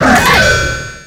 Cri d'Exagide dans Pokémon X et Y.